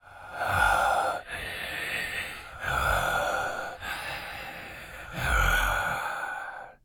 SH_oddech.ogg